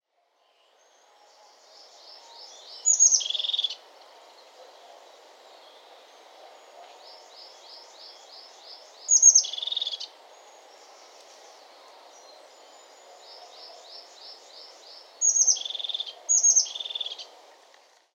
Kuifmees geluid
Deze Europese vogel mengt scherpe, bibberende geluiden met een ratelend “brr-dr-dr-dr” en soms een helder “zi-zi-zu-zi”.
Je hoort vaak een snorrend geluid, dat begint met een zacht “tsiet”.
Je hoort typische roepjes, zoals het schelle “brr-dr-dr-dr” en een hoog “zi-zi-zu-zi”.
Het geluid is scherp en bibberend.